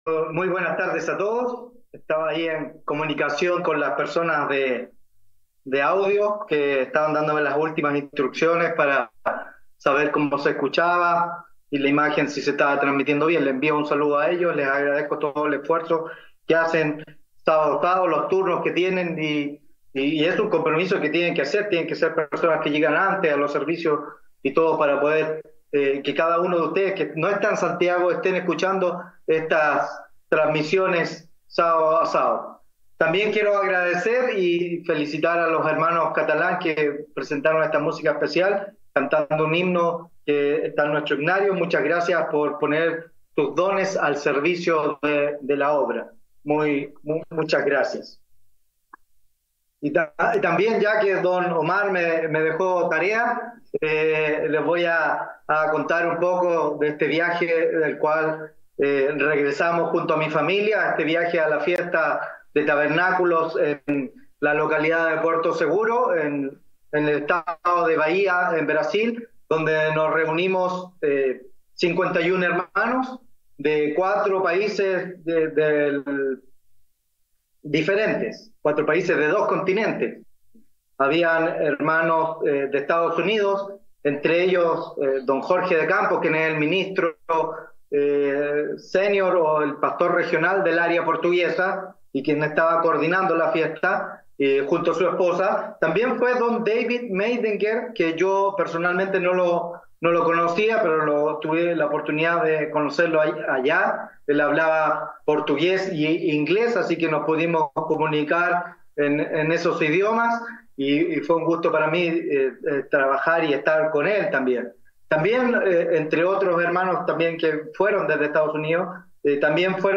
Given in Temuco